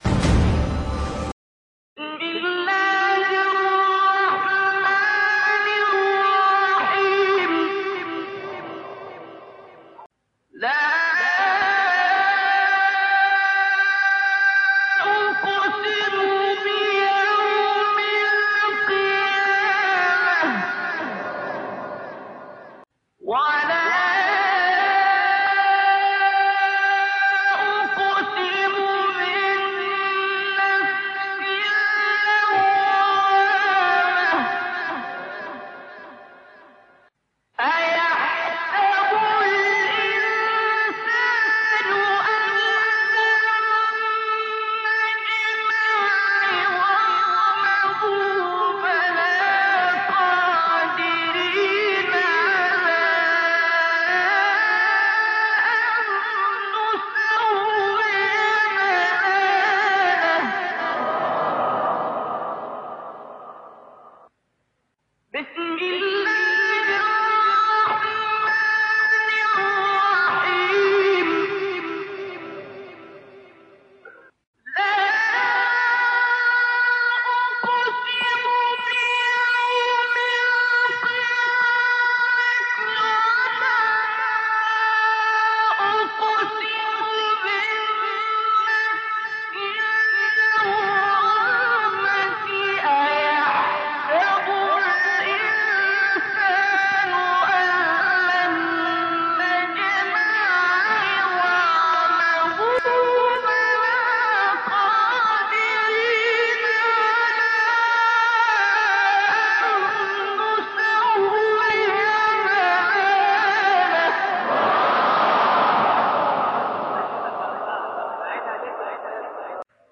صوت | تلاوت عبدالباسط از سوره «قیامة»
تلاوت شنیدنی استاد عبدالباسط عبدالصمد از سوره «قیامة» تقدیم مخاطبان ایکنا می‌شود.